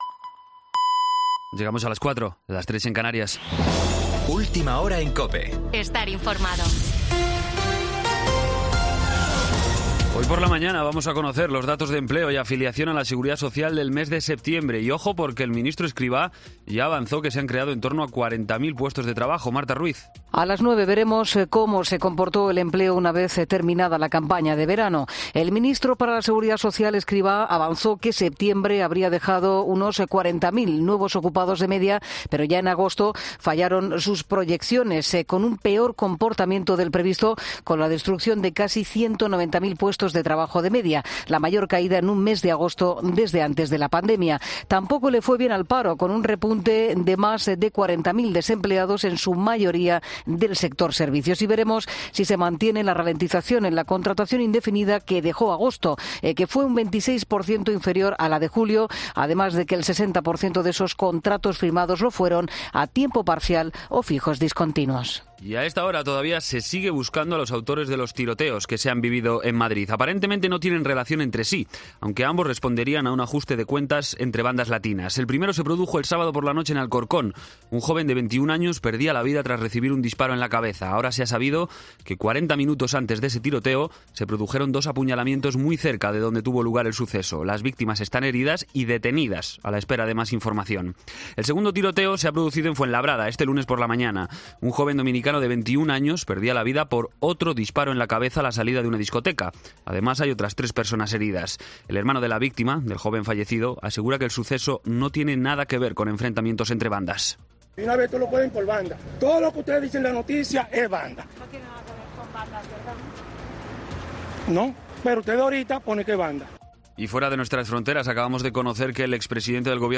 Boletín de noticias COPE del 04 de octubre a las 04:00 hora